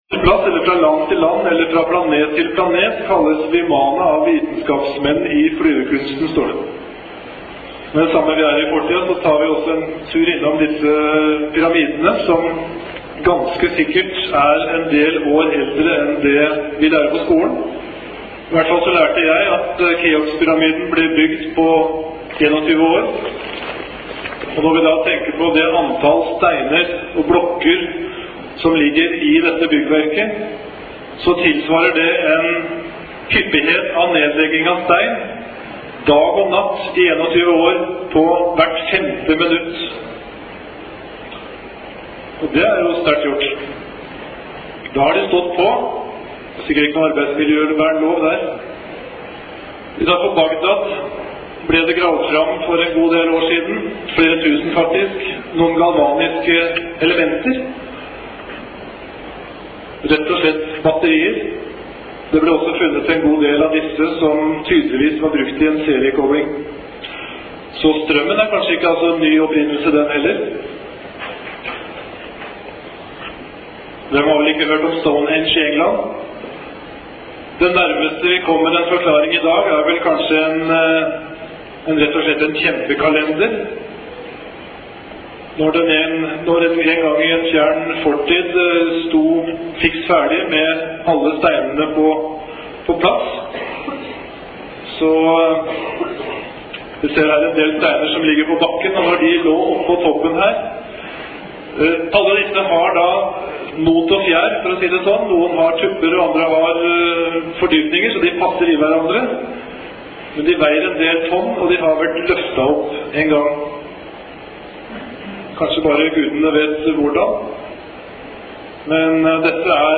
UFO foredrag i Oslo Spektrum 8 November 1998.
Denne siden inneholder redigerte lydopptak fra de foredrag som ble holdt p� sidescene vest i Oslo Spektrum den 8 November 1998.